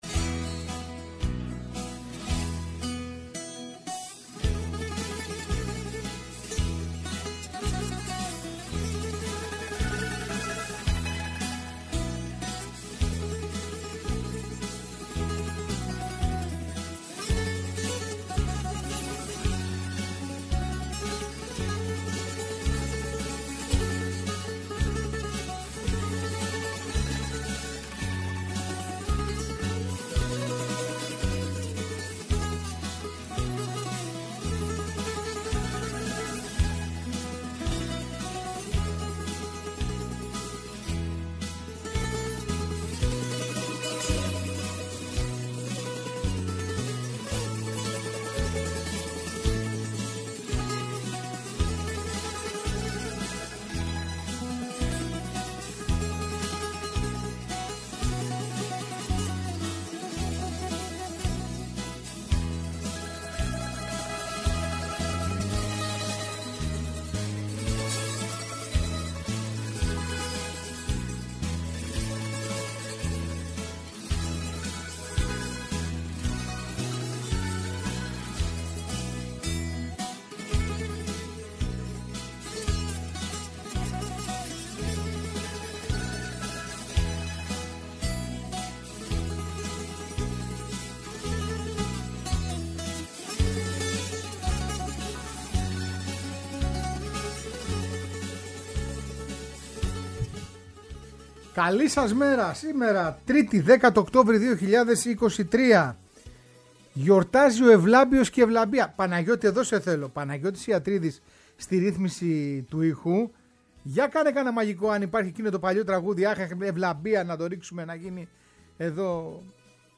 Παράλληλα ακούμε και θαυμάζουμε μαζί… τον ύμνο της Μπαρτσελόνα και του Ηρακλή Βερβένων!